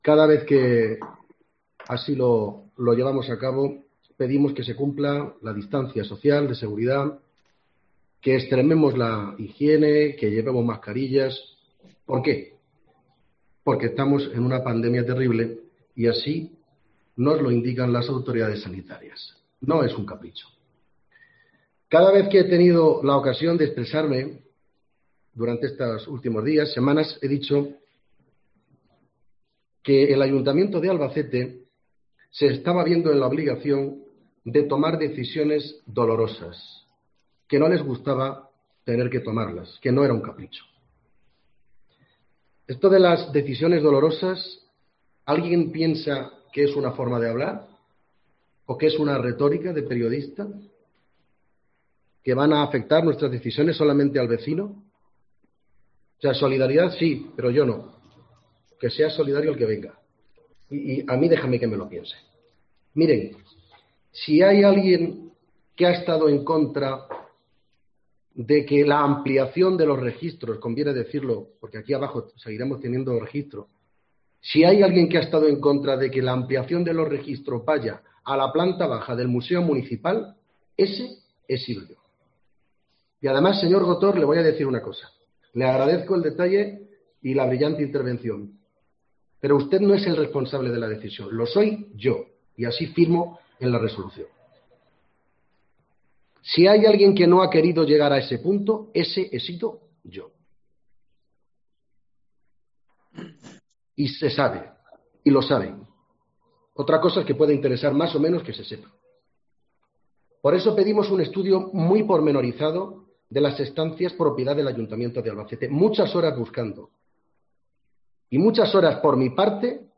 INTERVENCIÓN PLENARIA
Contundente discurso de Vicente Casañ tras ser rechazada la moción presentada por el Grupo Popular sobre el traslado del Registro Municipal al Museo del Altozano